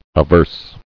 [a·verse]